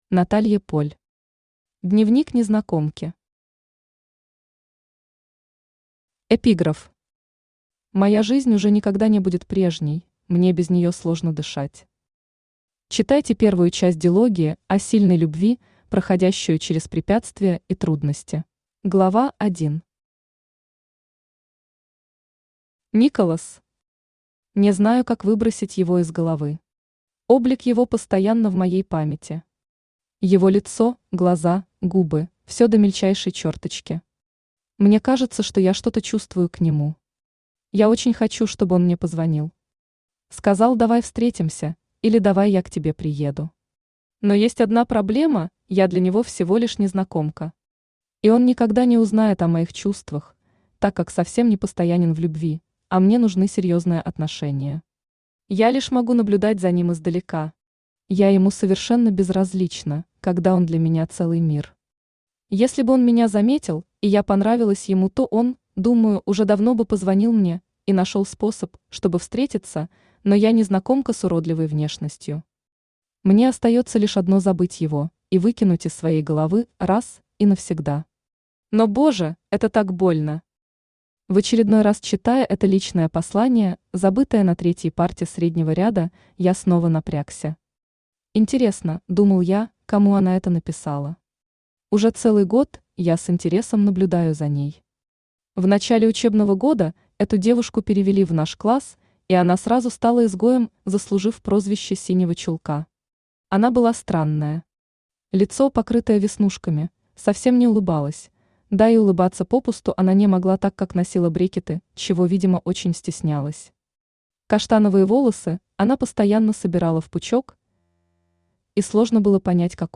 Аудиокнига Дневник незнакомки | Библиотека аудиокниг
Aудиокнига Дневник незнакомки Автор Наталья Поль Читает аудиокнигу Авточтец ЛитРес.